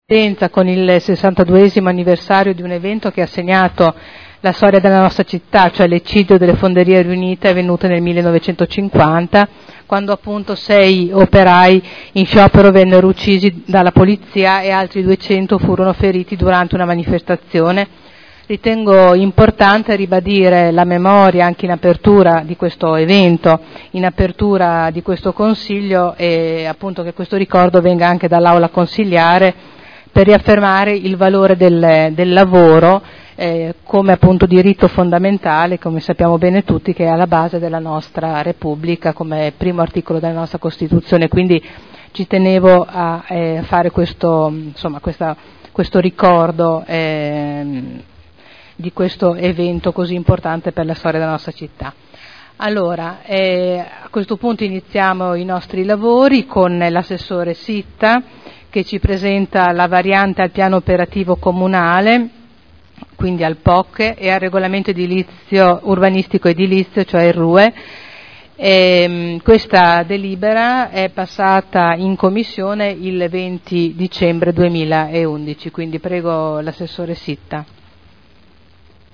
Il Presidente Caterina Liotti apre i lavori del Consiglio e ricorda l'eccidio delle fonderie del 9 gennaio 1950.